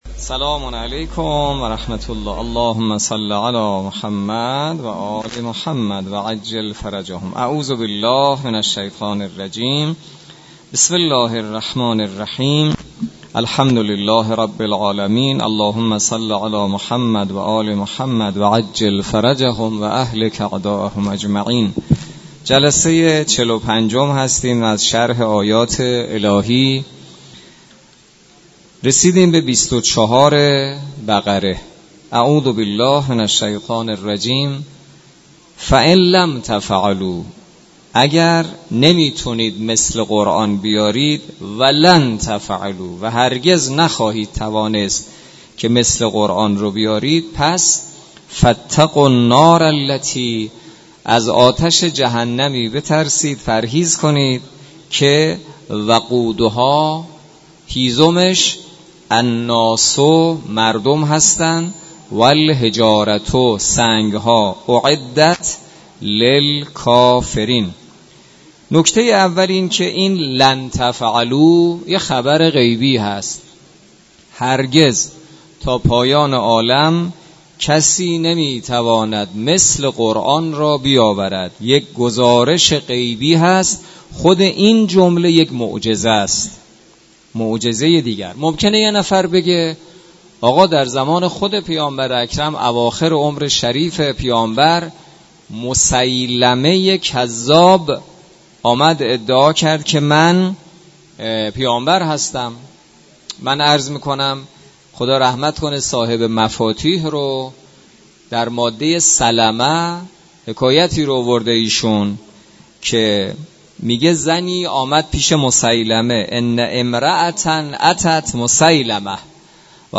برگزاری بیست و نهمین جلسه تفسیر سوره مبارکه بقره توسط امام جمعه کاشان در مسجد دانشگاه.